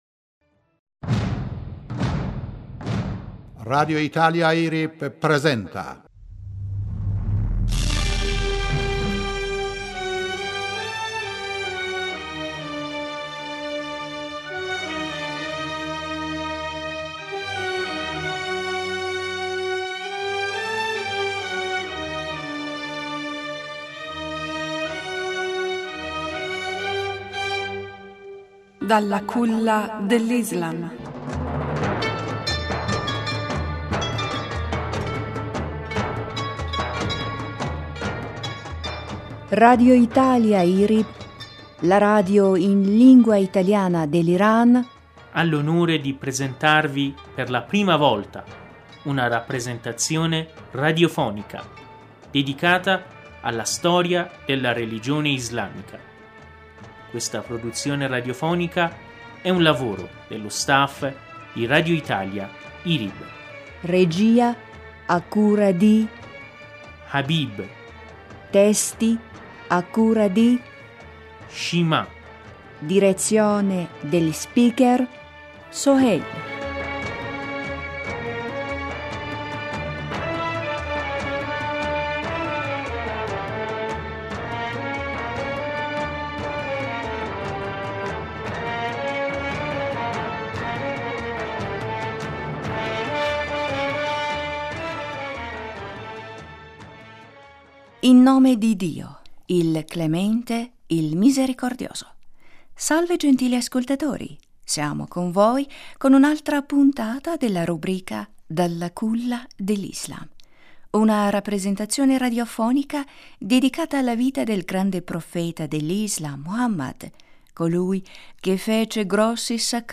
Siamo con voi con un’altra puntata della rubrica “Dalla Culla dell’islam” una rappresetazione radiofonica dedicata alla vita del grande Porfeta dell’islam, Muhammad(as), colui che fece grossi sacrifici per divulgare il verbo divino tra l’umanità.